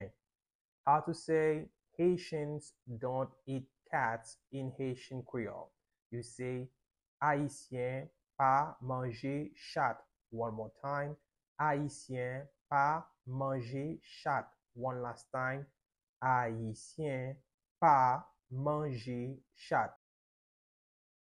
Pronunciation:
8.How-to-say-Haitian-dont-eat-cats-–-Ayisyen-pa-manje-chat-in-Haitian-Creole.mp3